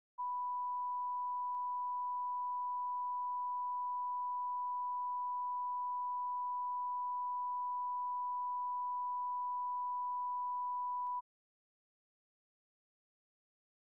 Location: White House Telephone
The President talked with Charles W. Colson.
[Unintelligible]